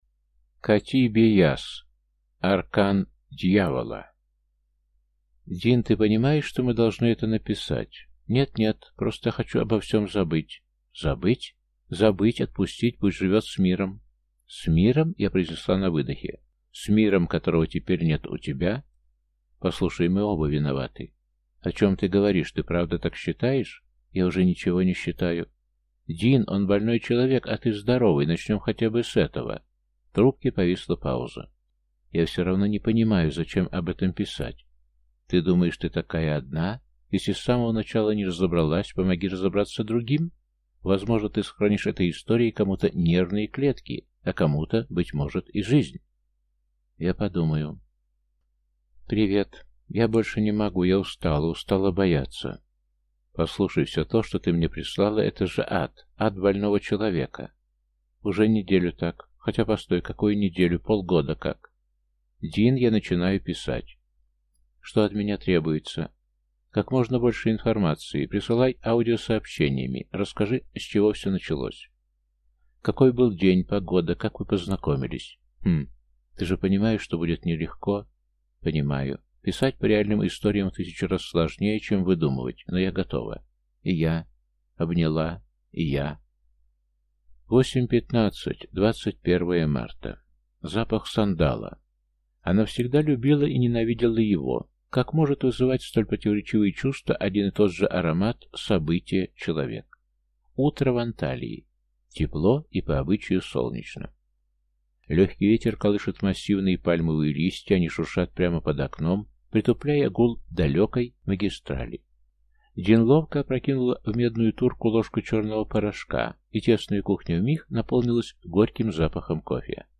Аудиокнига Аркан Дьявола | Библиотека аудиокниг
Прослушать и бесплатно скачать фрагмент аудиокниги